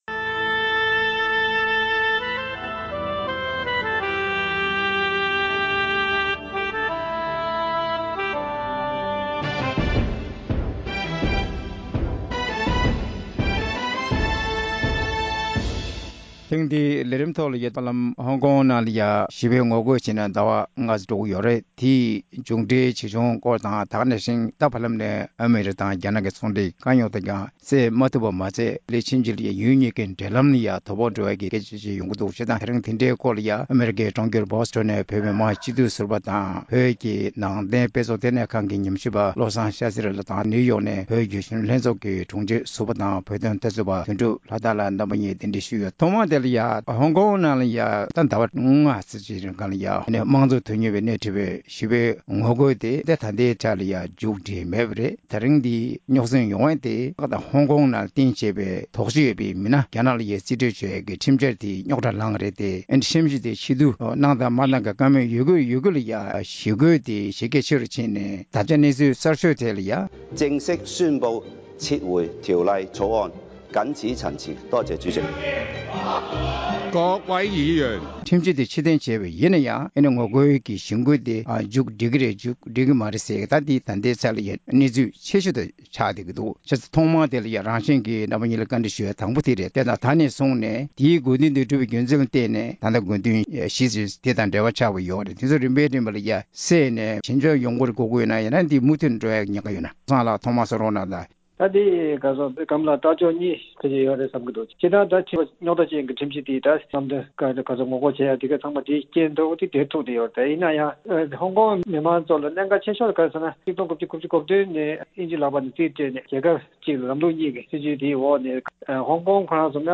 ཧོང་ཀོང་ནང་མང་ཚོགས་ཀྱི་ཞི་རྒོལ་གྱི་མཇུག་འབྲས་ཇི་ཡོང་དང་ཨ་མི་རི་ཀ་དང་རྒྱ་ནག་དབར་ཚོང་འབྲེལ་གྱི་འཐབ་རྩོད་སོགས་དང་འབྲེལ་བའི་སྐོར་གླེང་མོལ།